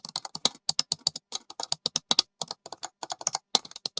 typing1.wav